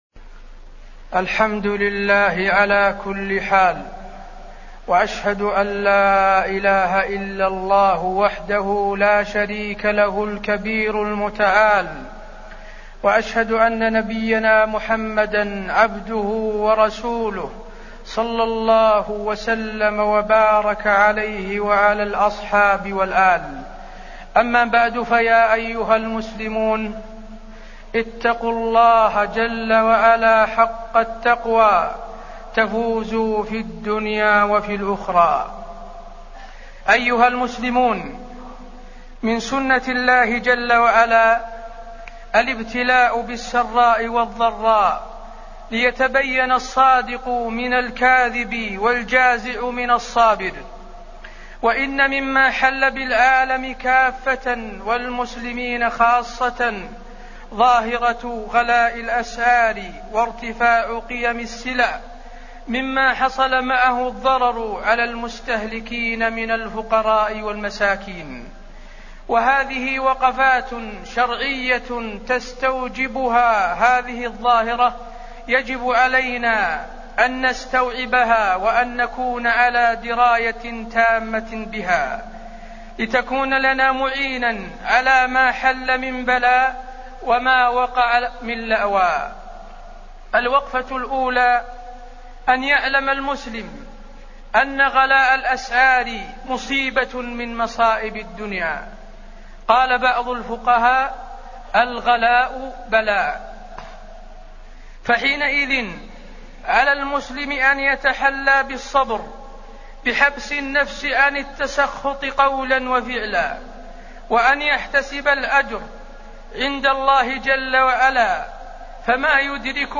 تاريخ النشر ١٦ محرم ١٤٢٩ هـ المكان: المسجد النبوي الشيخ: فضيلة الشيخ د. حسين بن عبدالعزيز آل الشيخ فضيلة الشيخ د. حسين بن عبدالعزيز آل الشيخ وقفات مع غلاء الأسعار